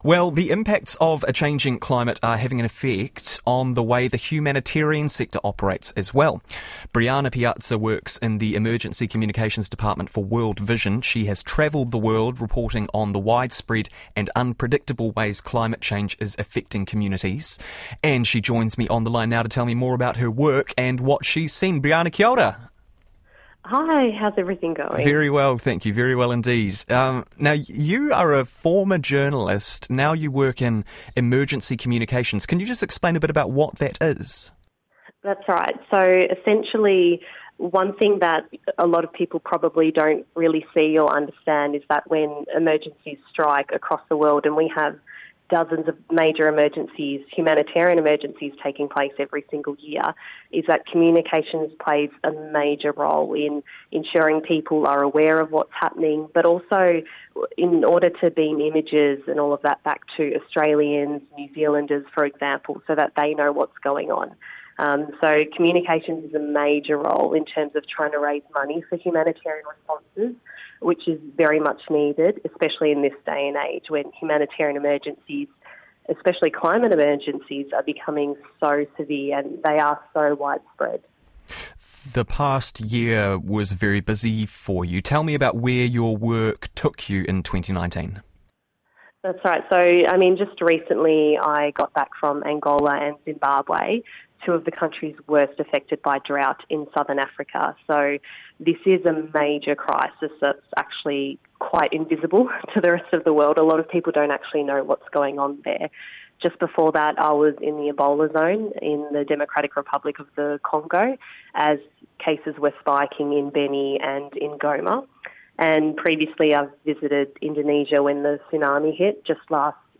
Live interview with Summer Times on Radio New Zealand